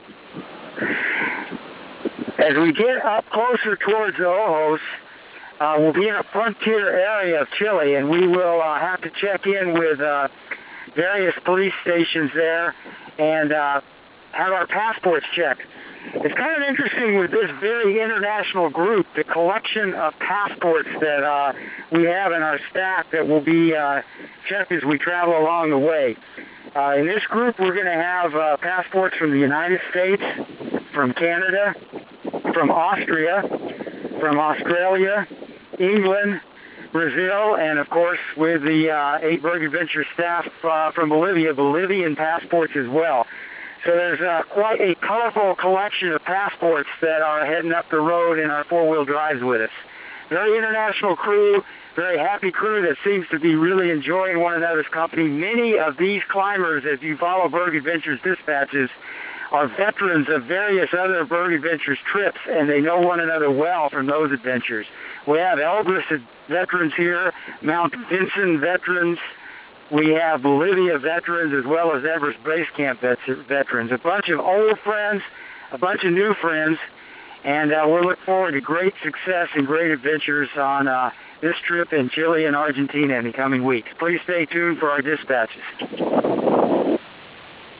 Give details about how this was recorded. Berg Adventures International: 2007 Aconcagua and Ojos del Salado Expedition Cybercast